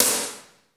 DANCE SD 5.wav